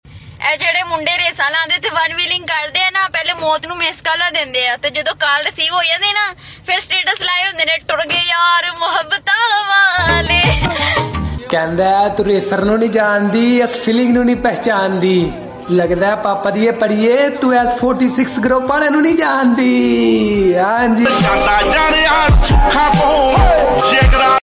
Honda 125 Bike Sound Effects Free Download